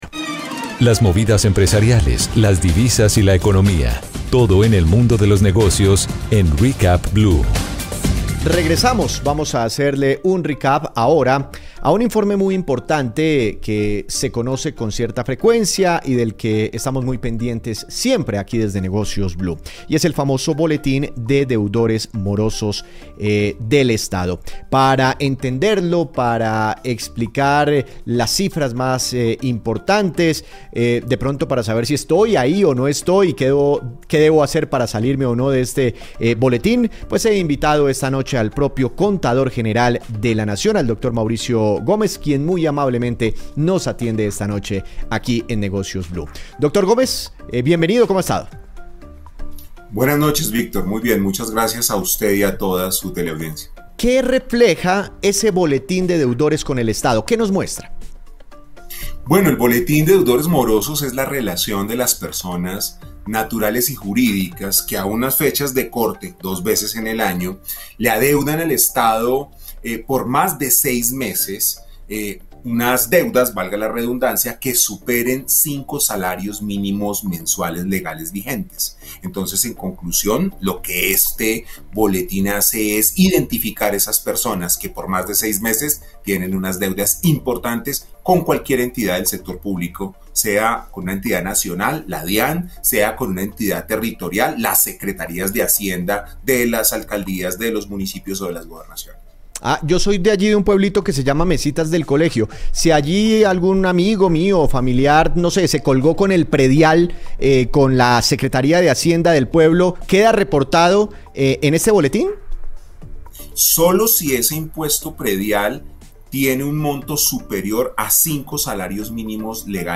Entrevista al Contador General de la Nación en Blu Radio (1)
entrevista-al-contador-general-de-la-nacion-en-blu-radio-1-